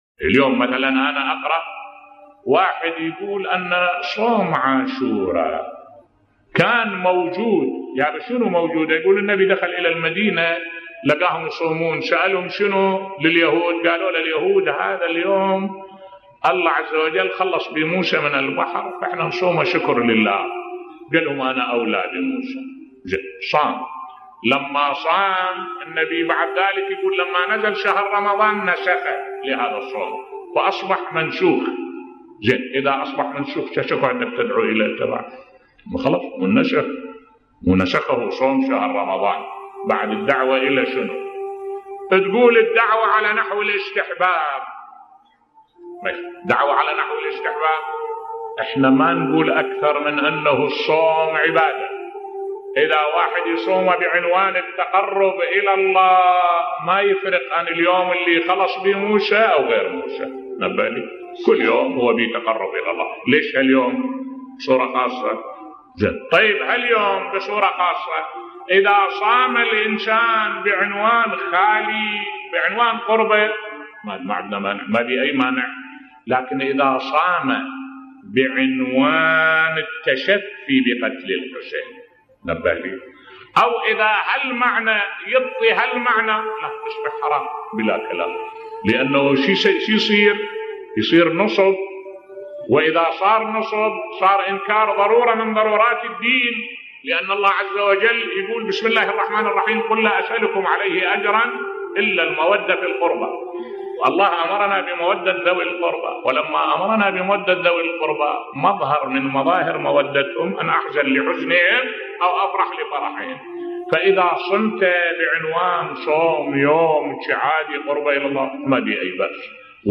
ملف صوتی صيام يوم عاشوراء بصوت الشيخ الدكتور أحمد الوائلي